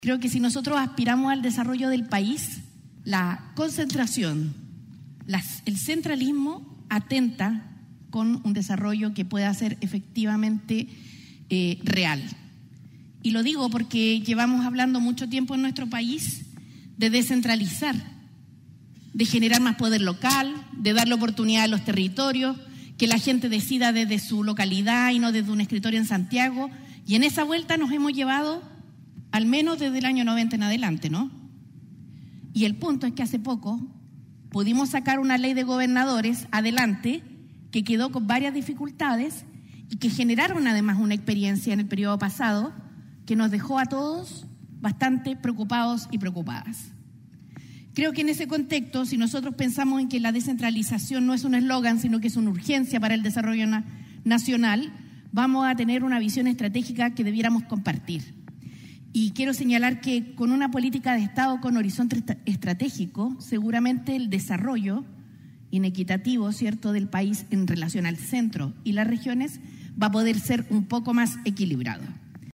“Descentralización para avanzar” fue el lema de la Cumbre de las Regiones 2025, realizada este lunes en el Teatro Biobío y organizada conjuntamente por Corbiobío, el Gobierno Regional, Desarrolla Biobío y la Asociación de Gobernadores y Gobernadoras Regionales de Chile (Agorechi).